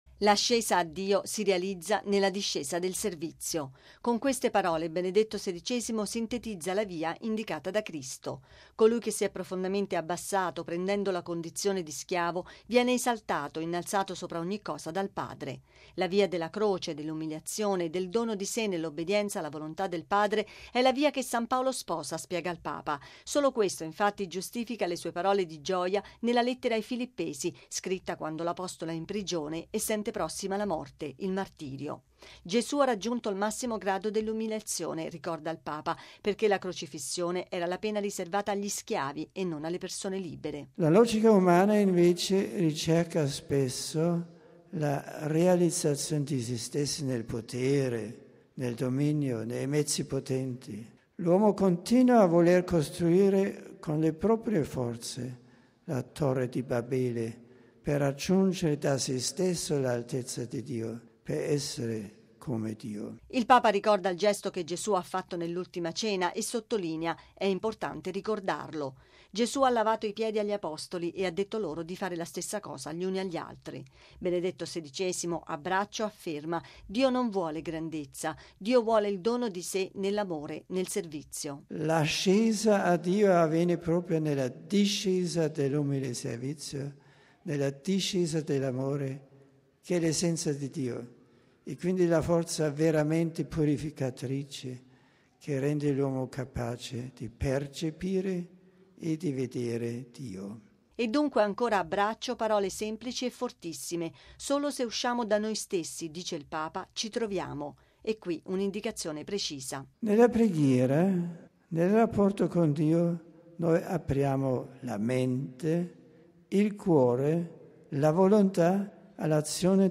Benedetto XVI all'udienza generale: Dio non vuole grandezza ma servizio
◊   La logica umana cerca potere, dominio, mezzi potenti ma la piena realizzazione è nello “svuotarsi di se stessi”. Lo ha detto il Papa all’udienza generale di questa mattina in Aula Paolo VI, ricordando la via indicata da Cristo e commentando la Lettera di San Paolo ai Filippesi, definita un “inno cristologico”.